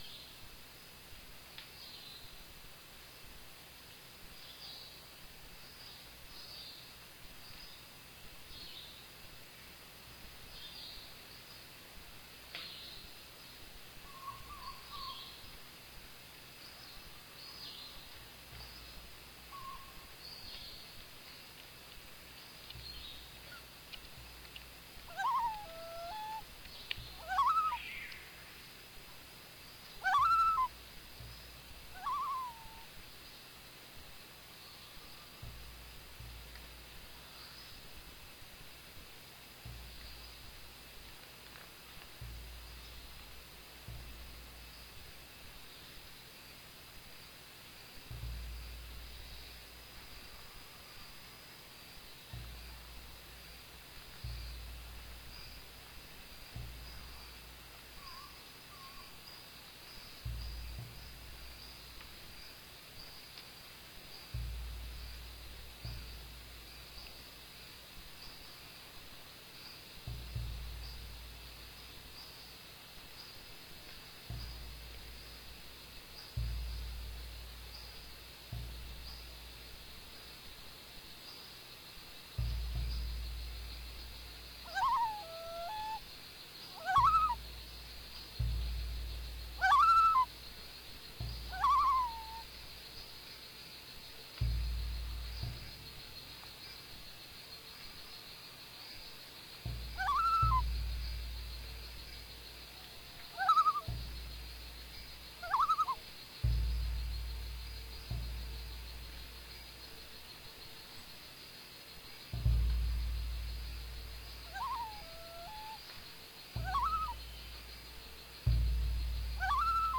Musique d'ambiance* (mieux vaut utiliser des écouteurs d'oreille plutôt que les enceintes basiques de l'ordi/téléphone, il y a des basses dans la piste audio)